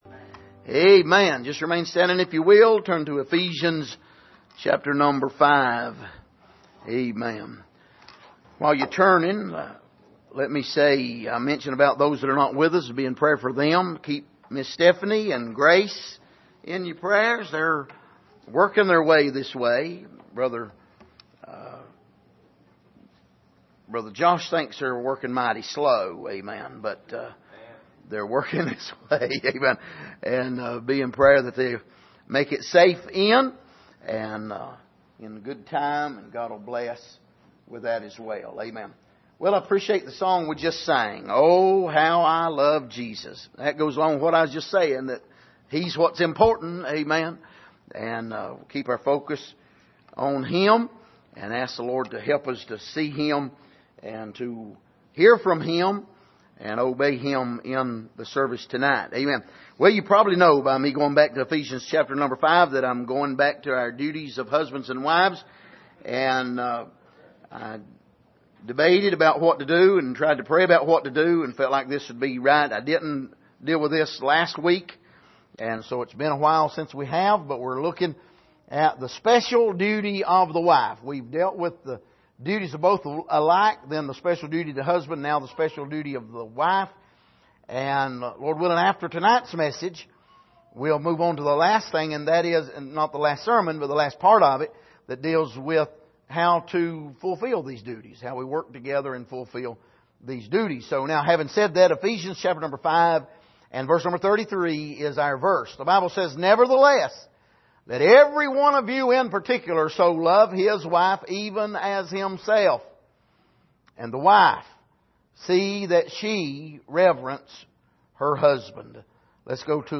Passage: Ephesians 5:33 Service: Sunday Evening